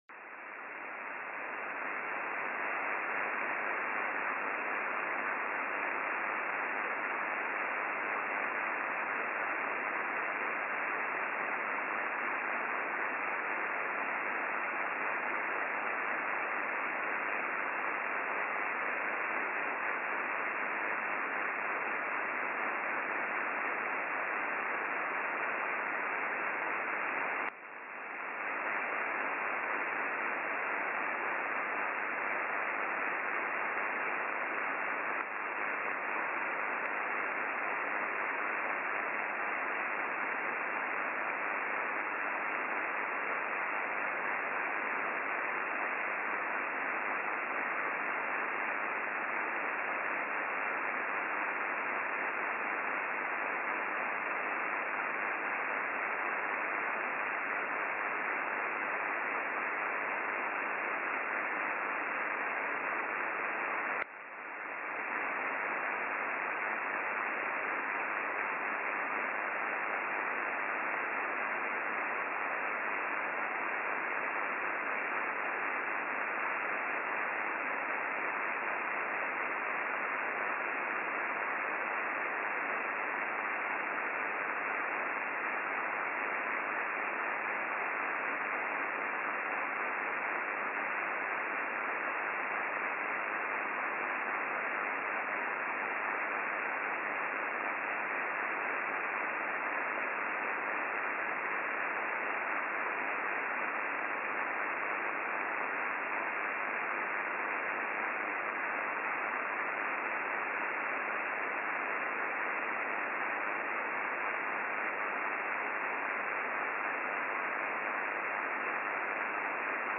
[1 Hour] Radio Static Noise ｜ Sleep & Relaxation. White Noise Sleep Relax. SSB Noise.mp3